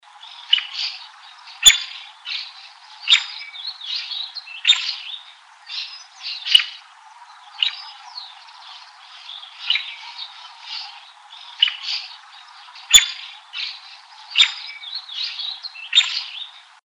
Scientific name: Colaptes melanochloros melanolaimus
English Name: Green-barred Woodpecker
Life Stage: Juvenile
Country: Argentina
Location or protected area: Reserva Ecológica Costanera Sur (RECS)
Condition: Wild
Certainty: Recorded vocal